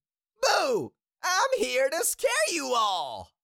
Cartoon Little Monster, Voice, I Am Here To Scare You All Sound Effect Download | Gfx Sounds
Cartoon-little-monster-voice-i-am-here-to-scare-you-all.mp3